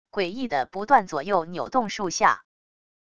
诡异的不断左右扭动数下wav音频